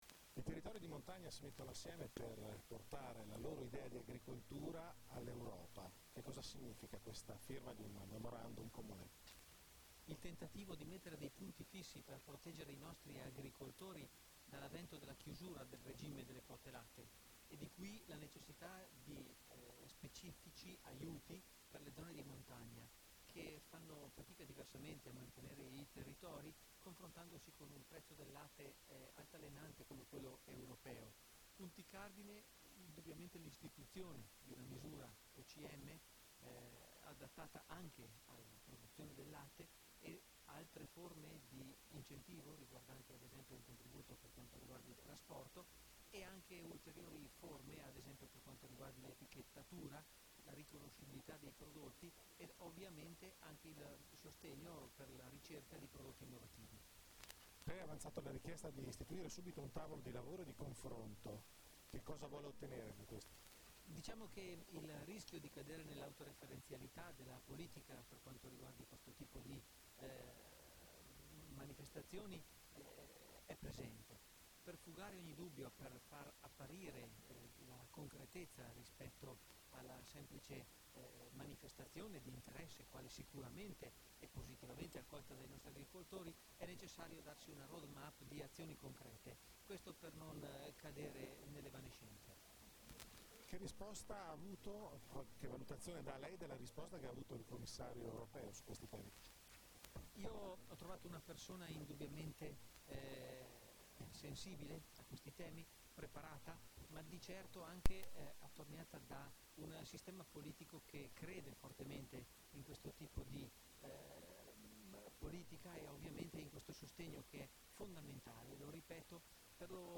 Intervista_Dallapiccola_Strasburgo.mp3